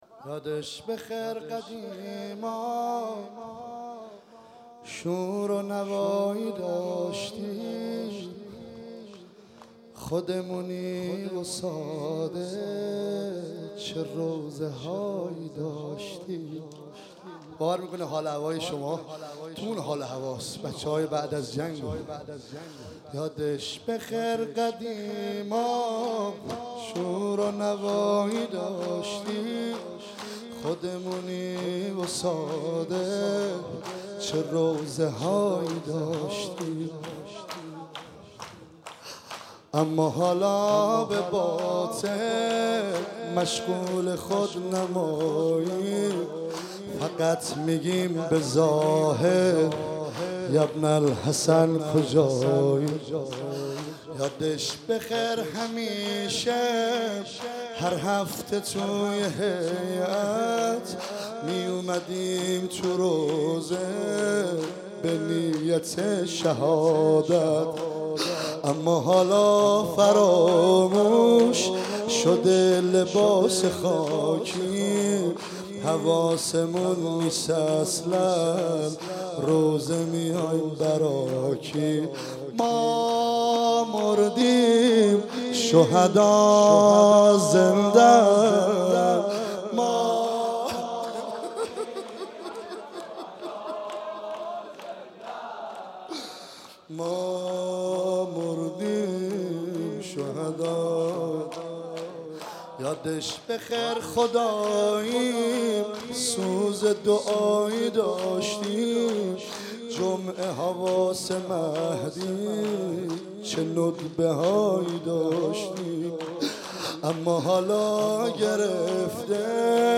عنوان شب چهارم محرم الحرام ۱۳۹۸
شور